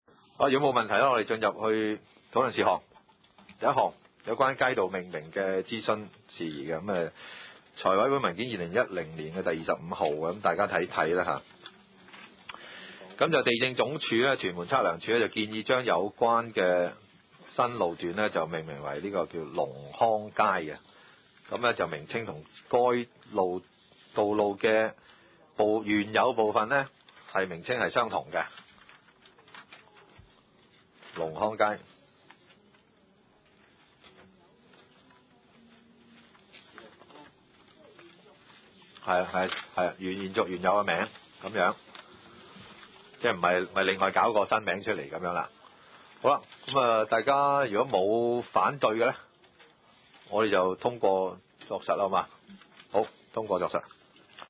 屯門區議會會議室